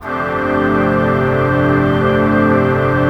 21PAD 01  -R.wav